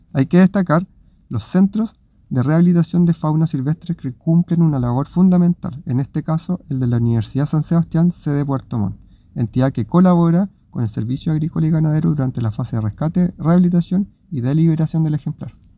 Además, Briones indicó la rehabilitación de fauna es clave para conservar el equilibrio ecológico y enfrentar las amenazas que afectan a especies como el zorro chilla, entre ellas, la pérdida de bosque nativo y el contacto con perros domésticos, que pueden agredirlos o transmitirles enfermedades.